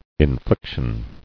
[in·flic·tion]